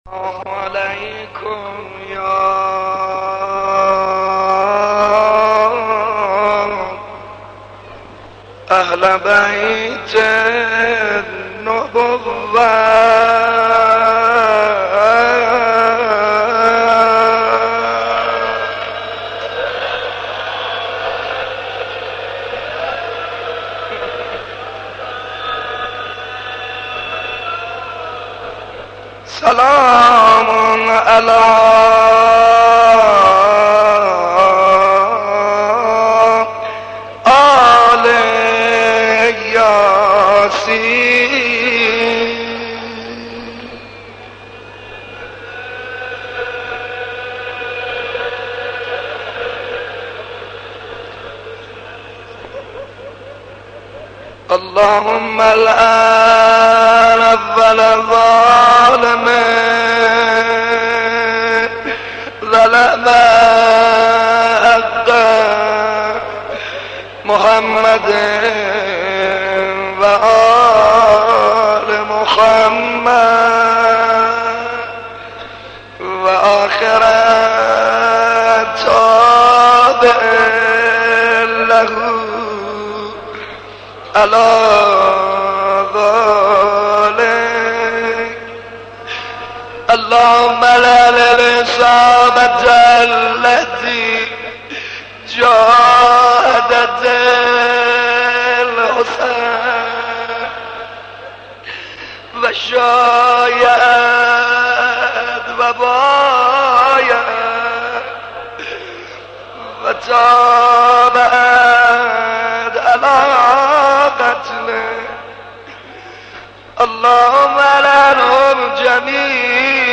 shabe 10 moharram 78 ark.mp3